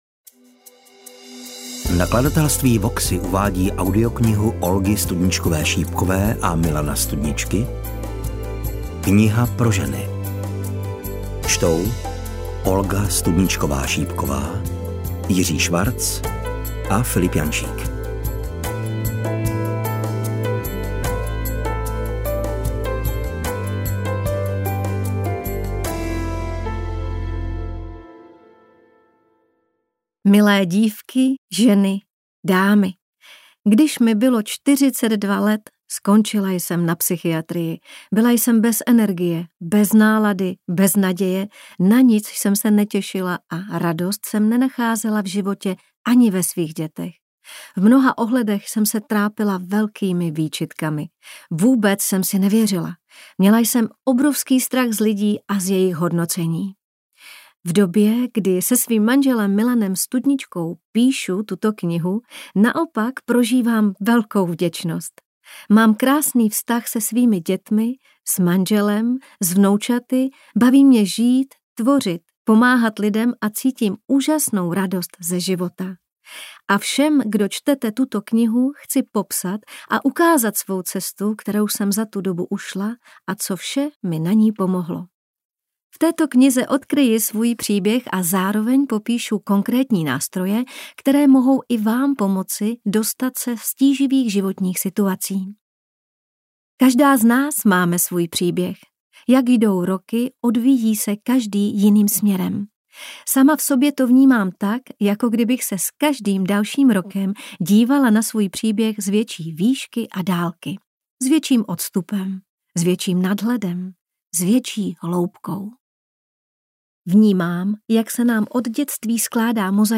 AudioKniha ke stažení, 29 x mp3, délka 4 hod. 51 min., velikost 264,1 MB, česky